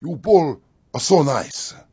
gutterball-3/Gutterball 3/Commentators/Master/zen_youbowlasonice.wav at 620778f53e7140d9414cafde9e18367a6aeae46f
zen_youbowlasonice.wav